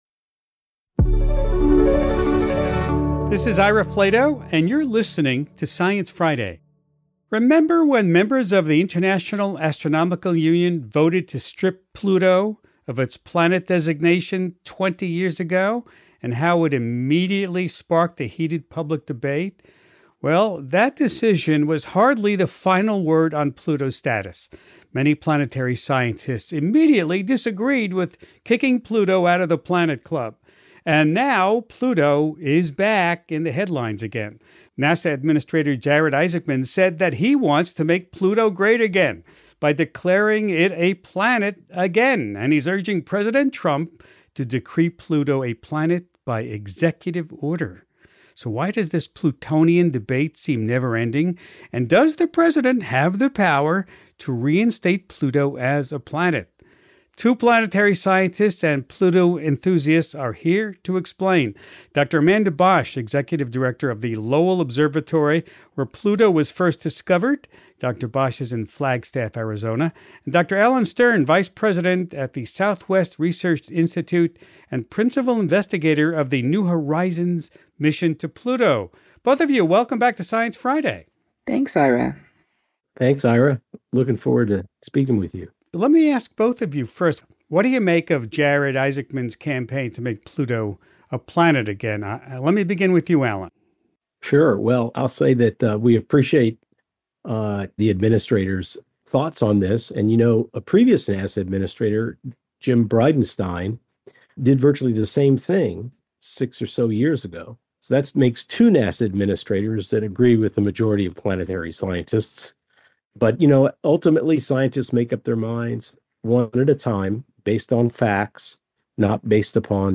Dr. Alan Stern is the vice president at the Southwest Research Institute and principal investigator of the New Horizons mission to Pluto.